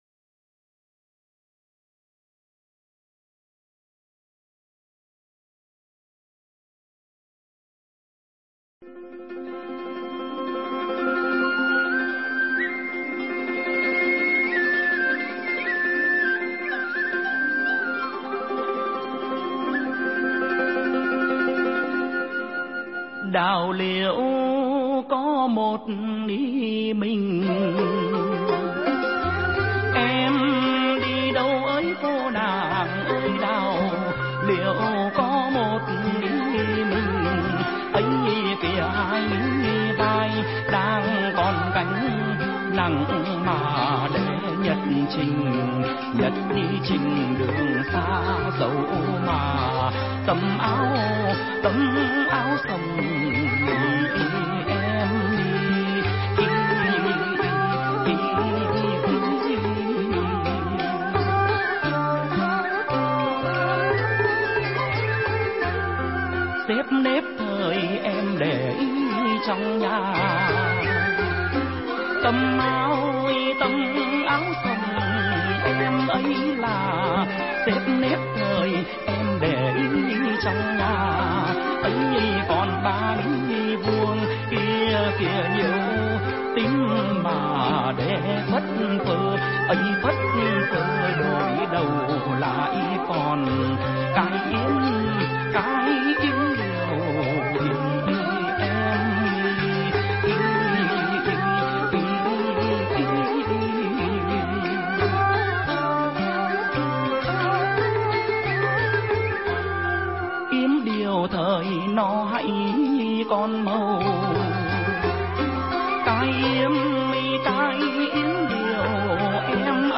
Tuyển Chọn Dân Ca Ví Dặm Mp3 Cổ